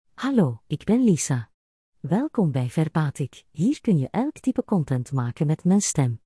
Lisa — Female Belgian Dutch AI voice
Lisa is a female AI voice for Belgian Dutch.
Voice sample
Listen to Lisa's female Belgian Dutch voice.
Lisa delivers clear pronunciation with authentic Belgian Dutch intonation, making your content sound professionally produced.